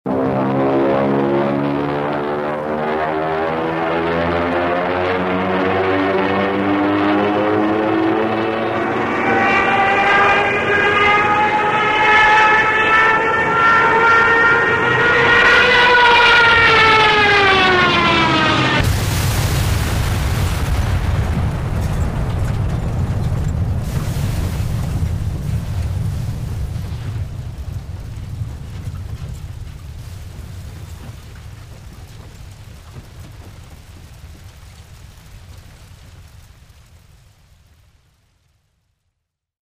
軍用機のcrash落事故 | 効果音.mp3 | 無料ダウンロード。
軍用機のcrash落事故: